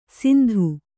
♪ sindhu